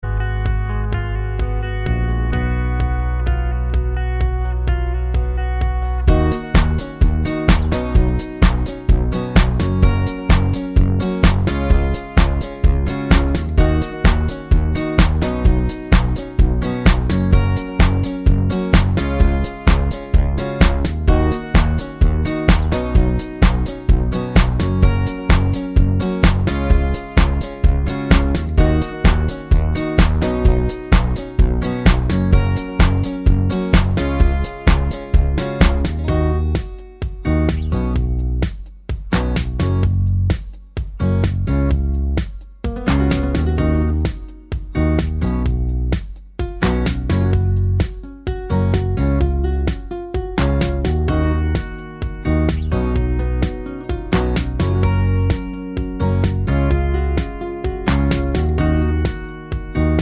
no Backing Vocals Country (Male) 4:06 Buy £1.50